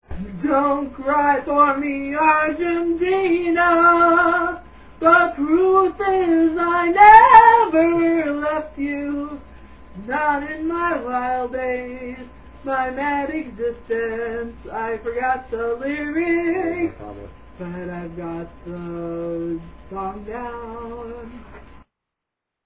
and you're looking for a diva with a classic voice